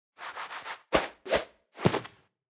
Media:Sfx_Anim_Super_Shelly.wavMedia:Sfx_Anim_Ultra_Shelly.wav 动作音效 anim 在广场点击初级、经典、高手和顶尖形态或者查看其技能时触发动作的音效
Sfx_Anim_Baby_Shelly.wav